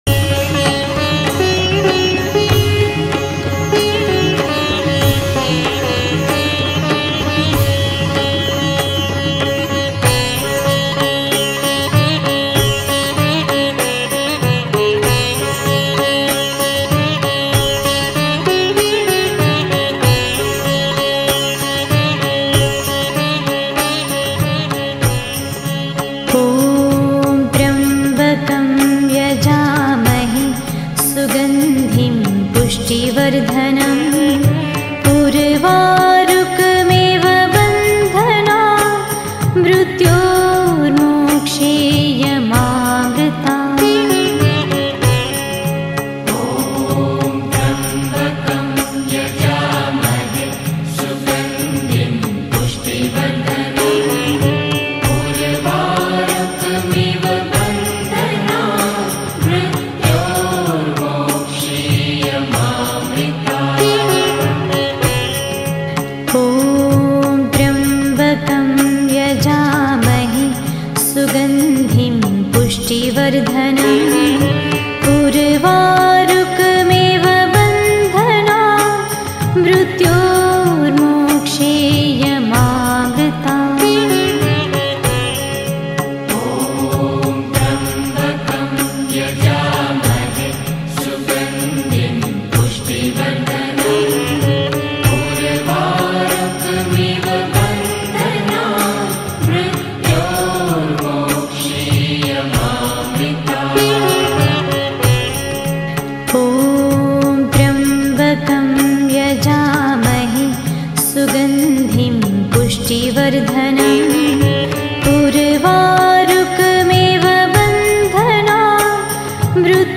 Mahadev Mantra Jaap